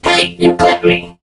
mech_crow_dies_01.ogg